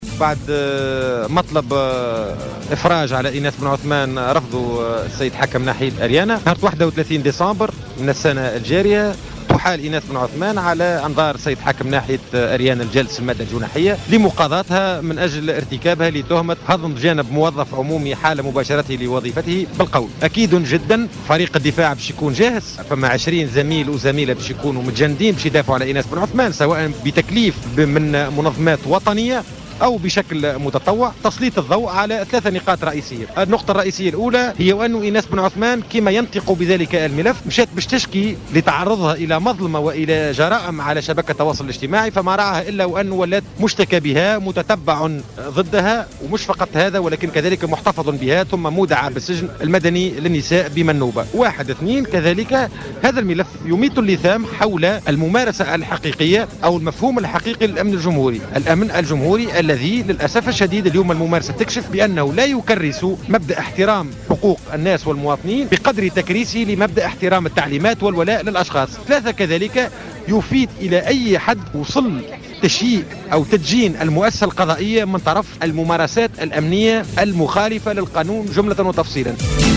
تصريح ل"جوهرة أف أم"